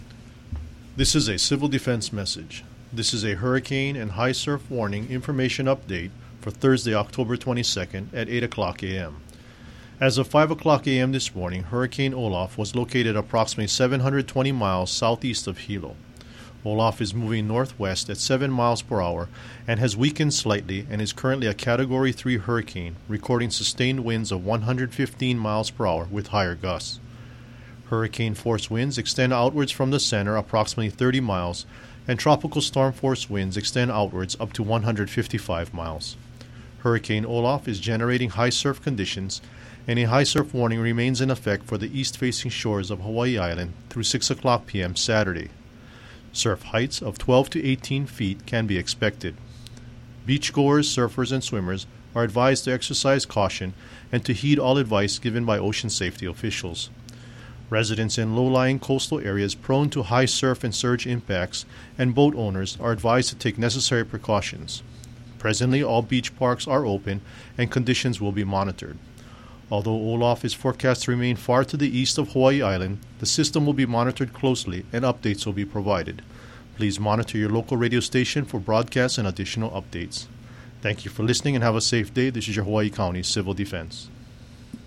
8 a.m. Hawaii County Civil Defense message on Hurricane Olaf and High Surf